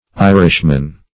Irishman \I"rish*man\, n.; pl. Irishmen.